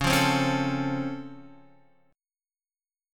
Db+M7 chord